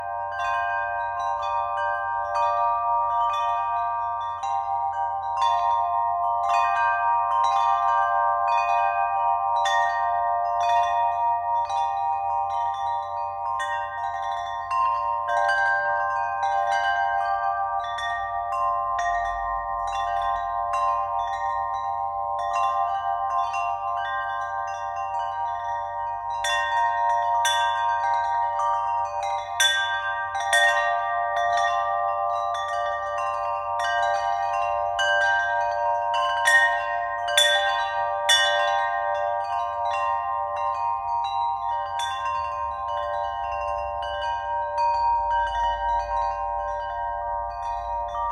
Carillon Terre                    Durée 06:57